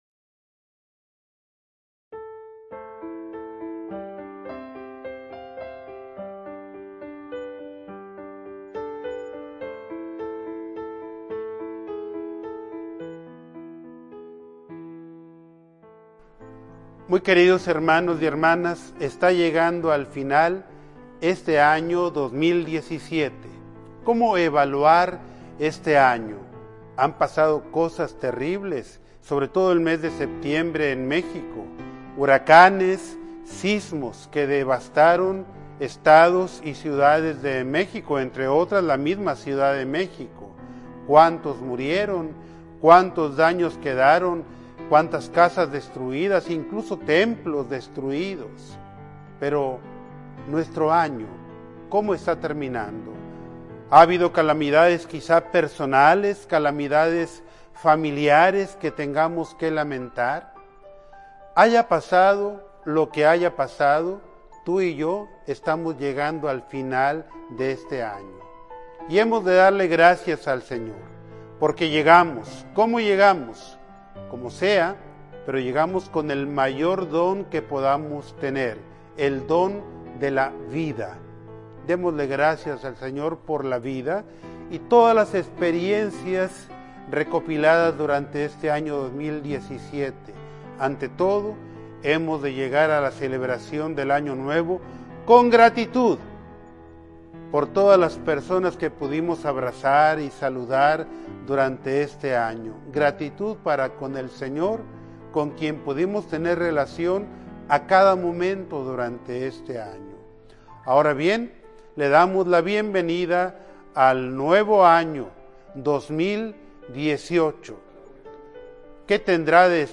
Mensaje episcopal del Año Nuevo 2018